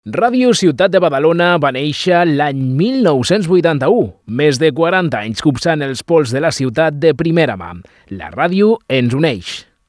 Veu enregistrada amb motiu del Dia mundial de la ràdio 2022.